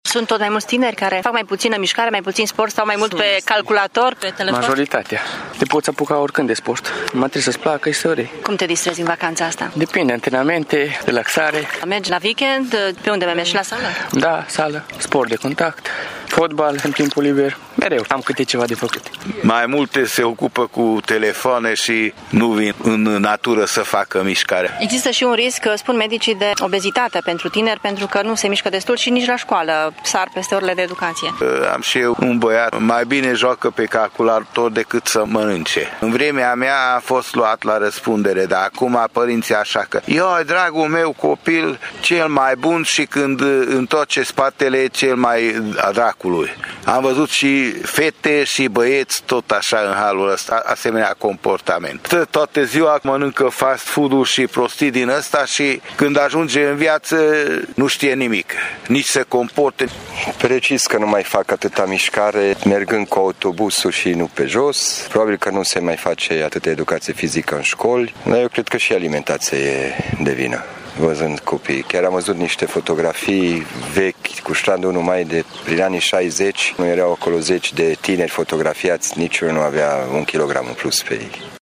Târgumureșenii conștientizează că viața sedentară, educația precară de acasă sau de la școală au condus la această stare de fapt în România: